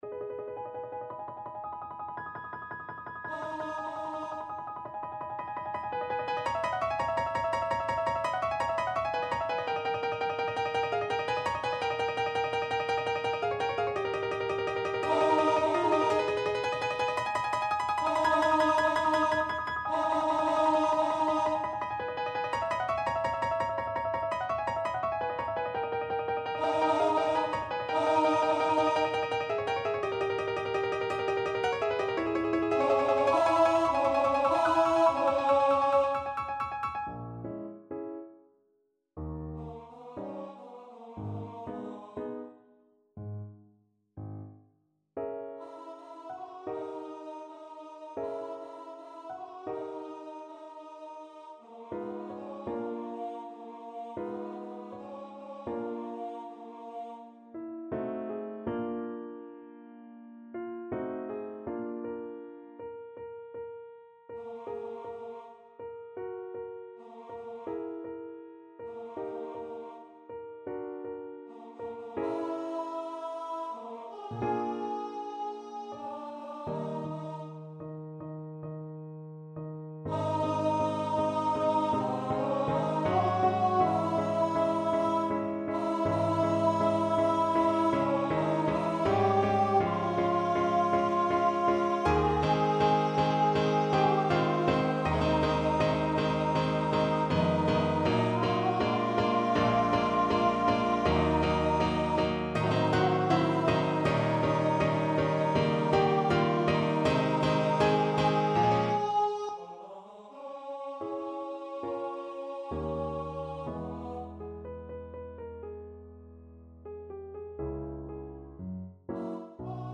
Free Sheet music for Tenor Voice
2/4 (View more 2/4 Music)
Ab minor (Sounding Pitch) (View more Ab minor Music for Tenor Voice )
Allegro giusto (=112) (View more music marked Allegro)
Tenor Voice  (View more Advanced Tenor Voice Music)
Classical (View more Classical Tenor Voice Music)